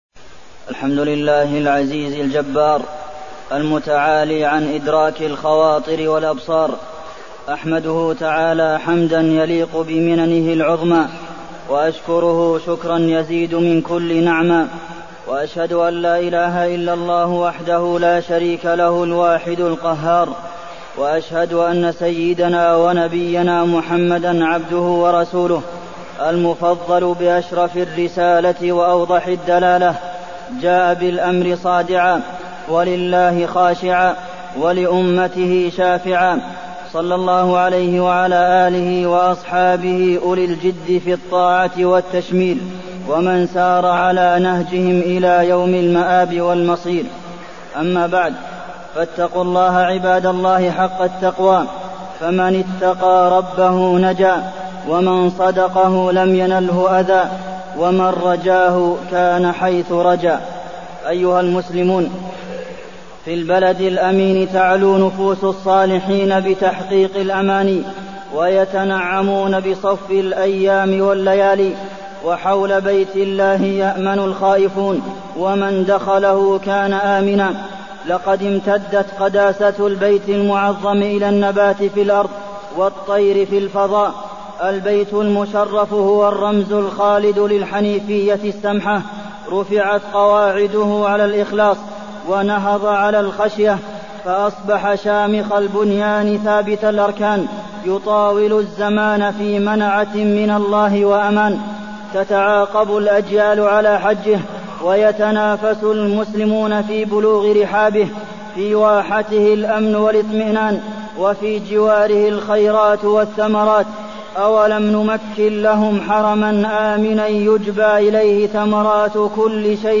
تاريخ النشر ٤ ذو الحجة ١٤٢٠ هـ المكان: المسجد النبوي الشيخ: فضيلة الشيخ د. عبدالمحسن بن محمد القاسم فضيلة الشيخ د. عبدالمحسن بن محمد القاسم الحج